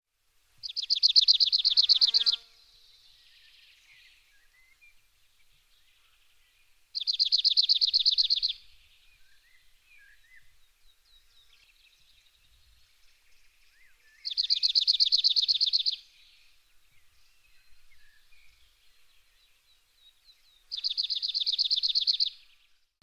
Bruant zizi - Mes zoazos
bruant-zizi.mp3